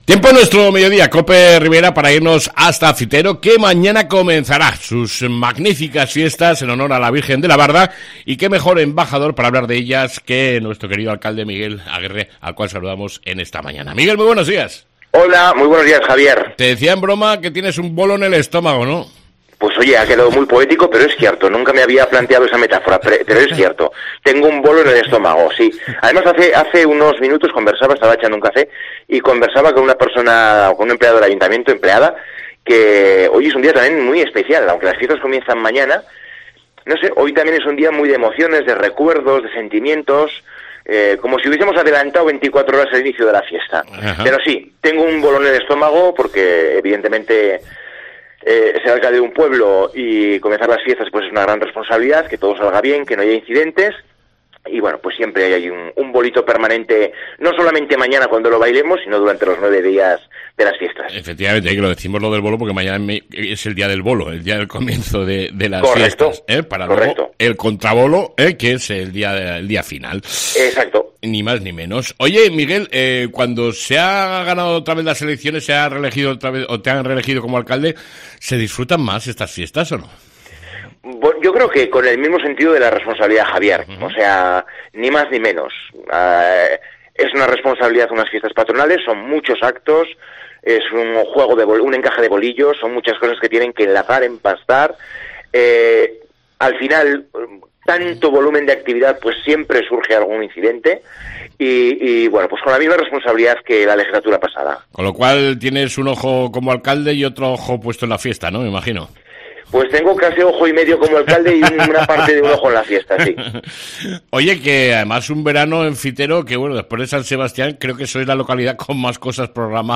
ENTREVISTA CON EL ALCALDE DE FITERO ,MIGUEL AGUIRRE YANGUAS